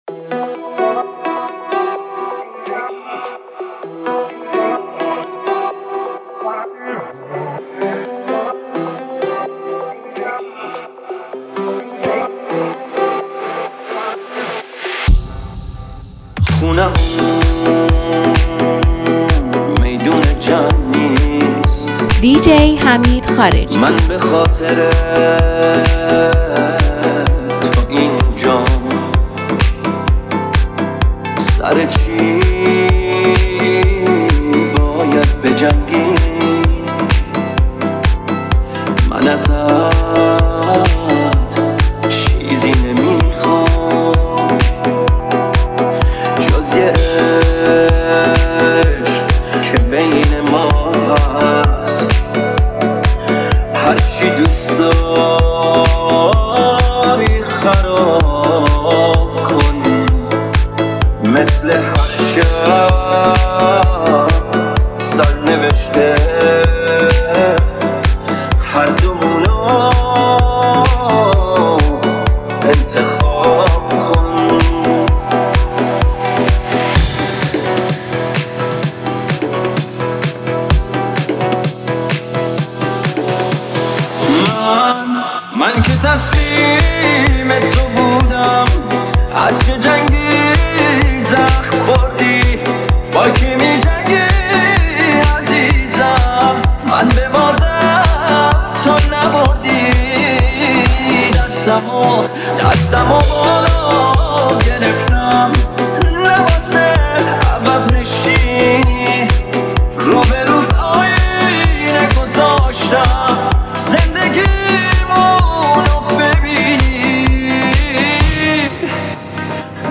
میکس احساسی